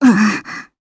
Gremory-Vox_Damage_jp_04.wav